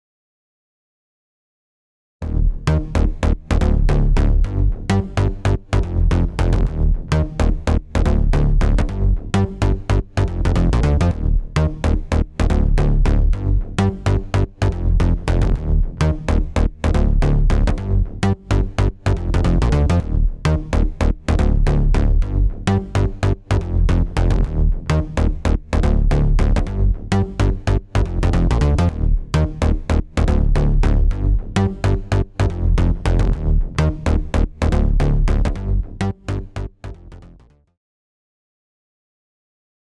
ON（ベース単体）
位相を整えることで、特に低音がしっかり引き締まります